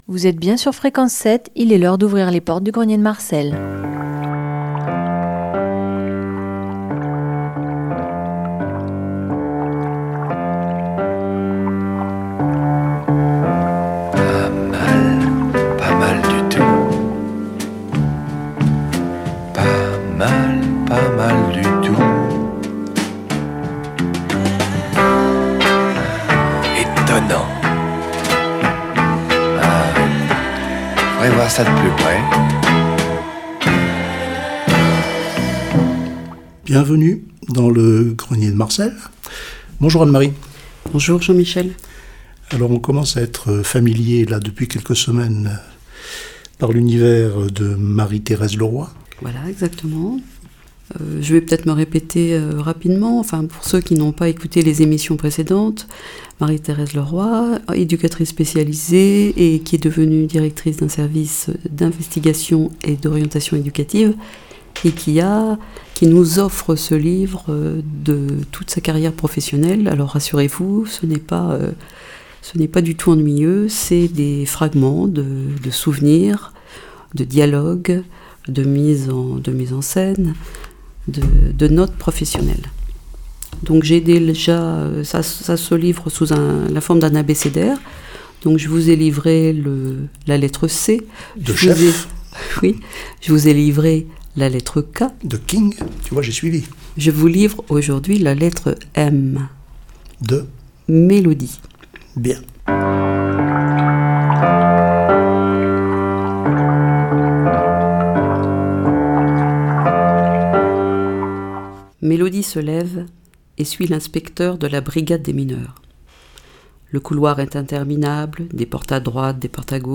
Émission Radio